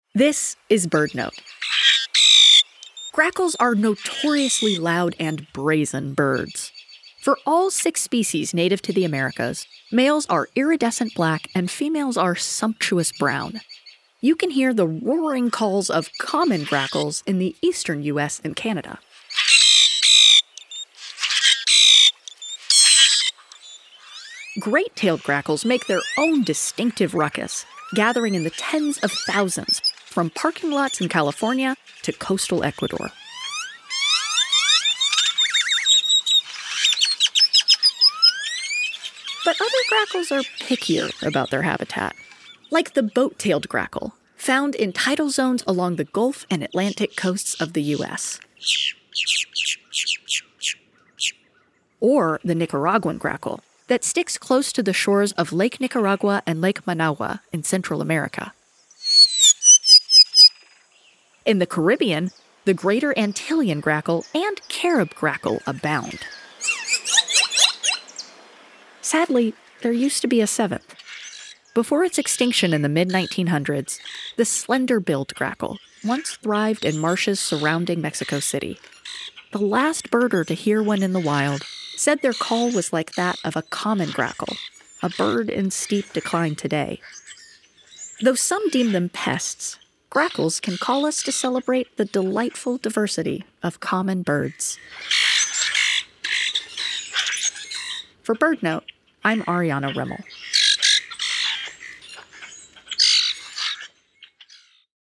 Grackles are notoriously loud and brazen birds. For all six species native to the Americas, males are iridescent black and females are sumptuous brown. Though these grackles may look the same, they each make their own distinctive ruckus.